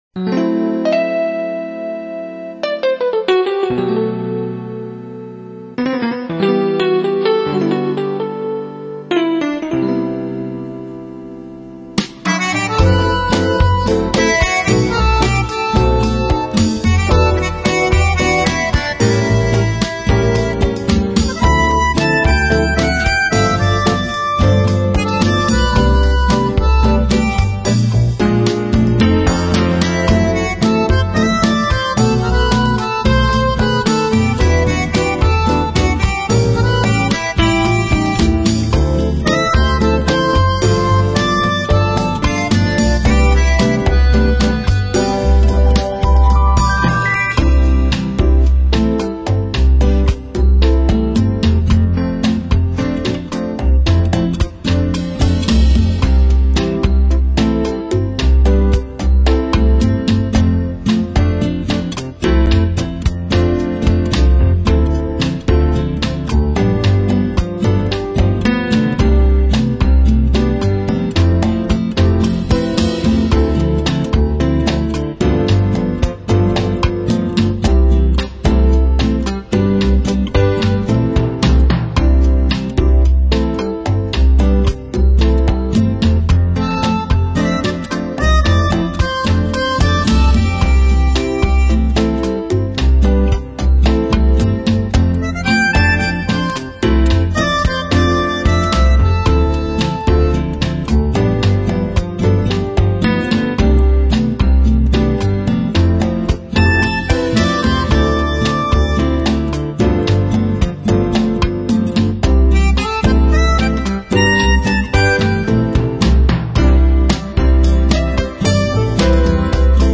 爵士风味